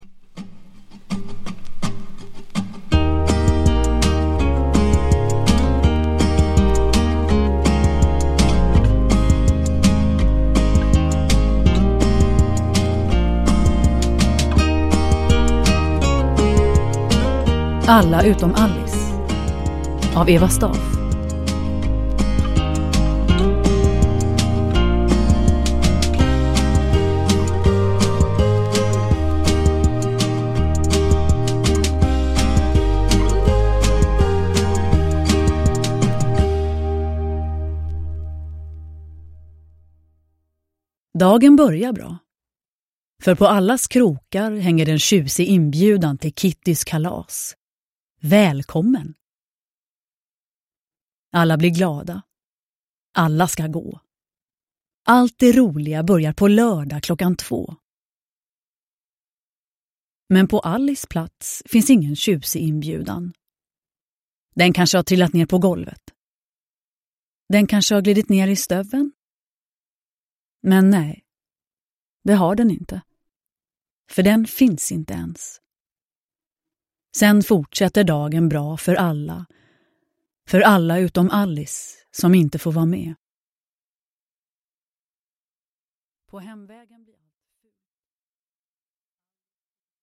Alla utom Allis – Ljudbok – Laddas ner
Uppläsare: Nina Zanjani